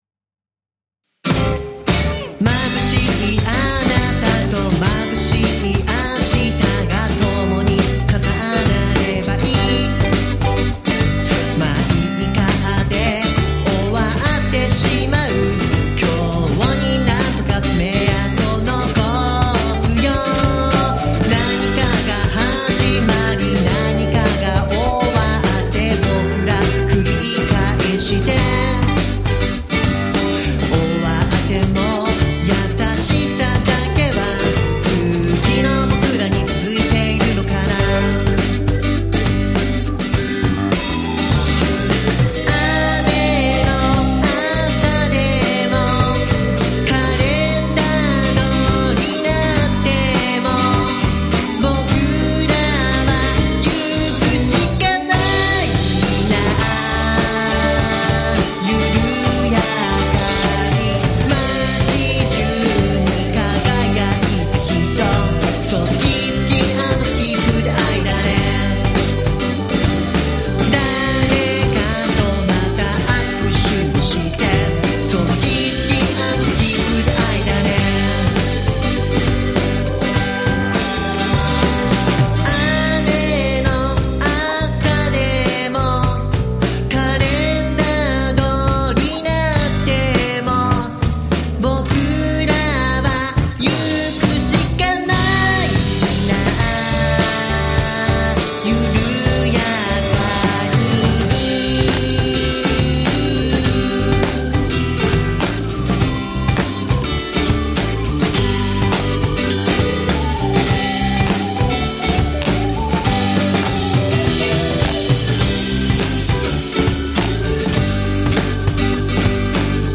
バンドサウンドをベースにクラップ、コーラス、鍵盤などを用いてキャッチーでポップな雰囲気に仕上げた。